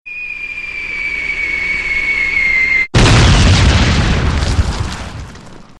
Bombing Raid Sound Effects ringtone free download